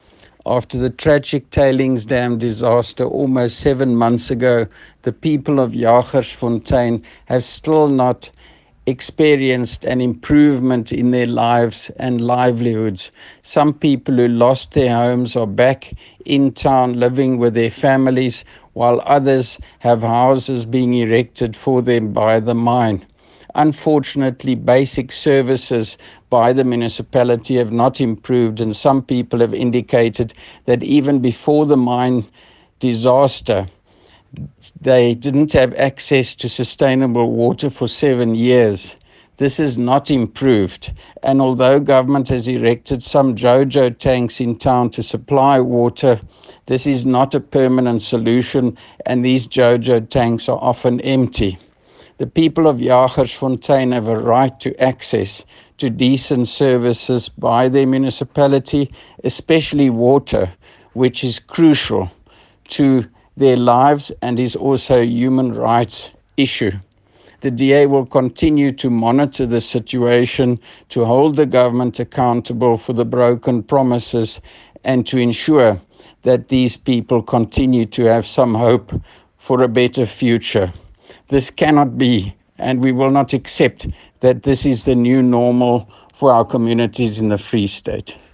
Afrikaans soundbites by Roy Jankielsohn MPL with images here, here and here